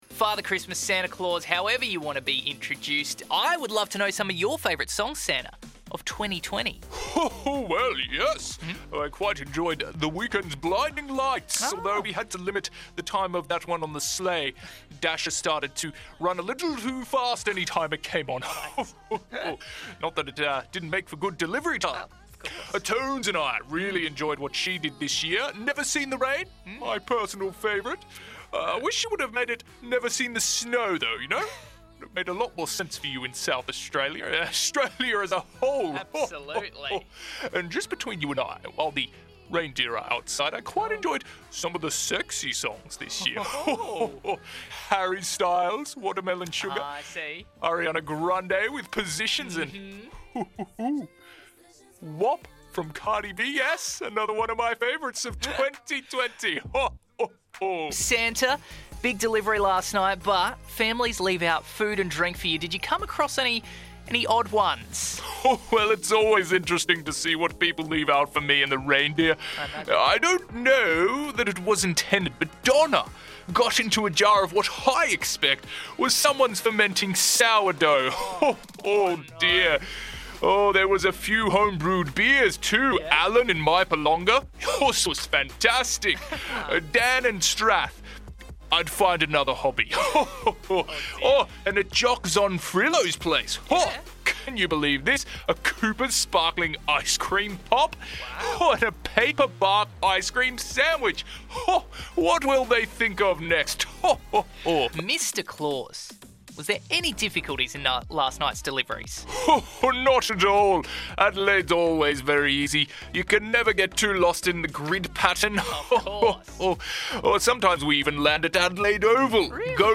Santa Claus Interview 2020